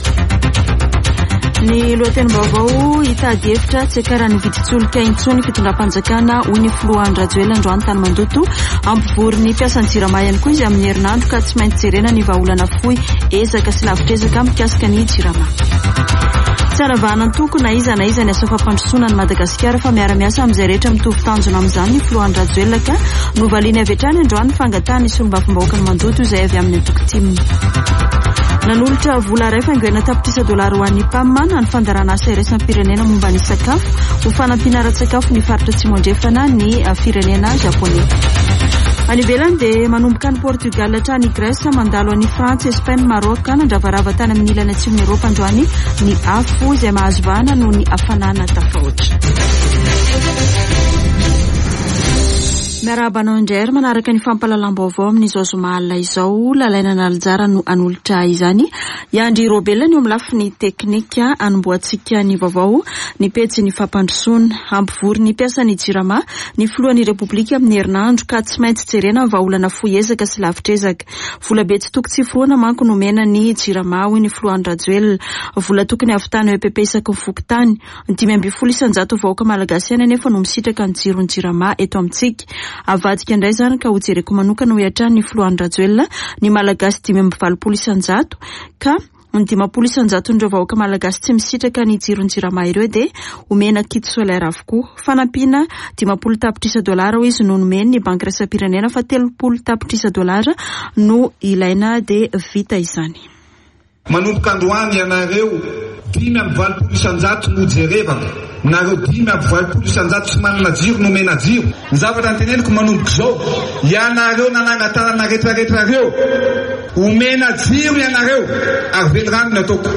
[Vaovao hariva] Zoma 15 jolay 2022